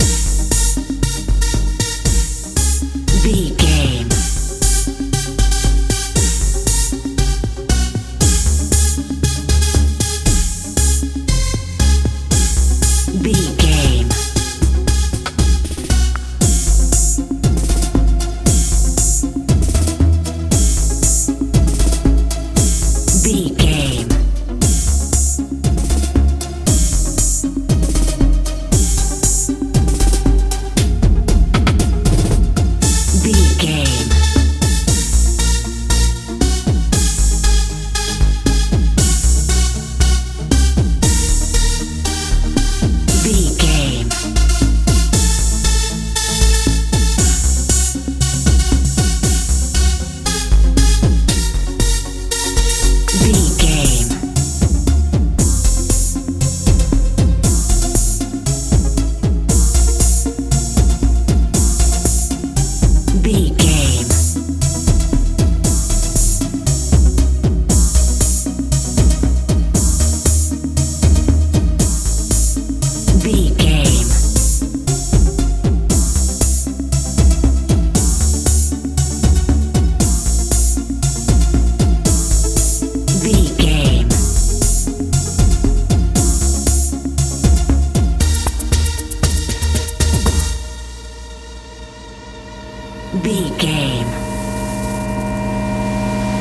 dance pop
Aeolian/Minor
E♭
bouncy
optimistic
dreamy
bass guitar
drums
synthesiser
80s
90s